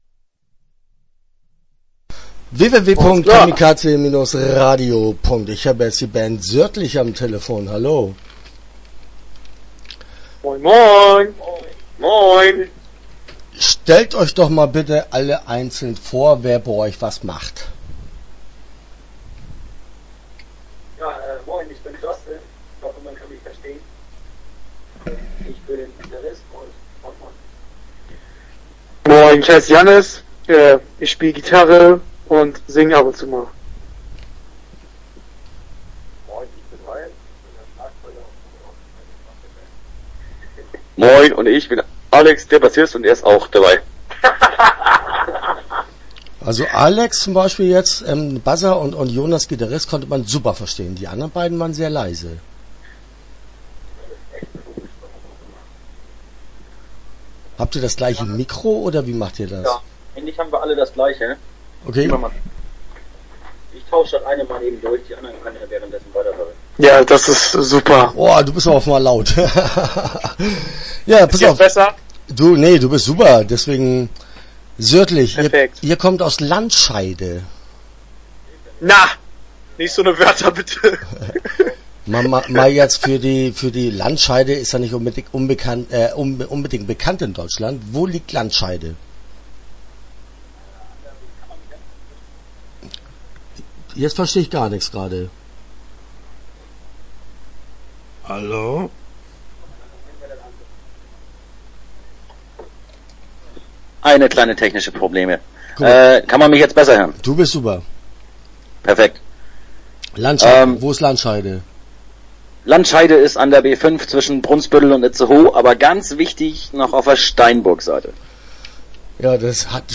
Sørdlich - Interview Teil 1 (10:46)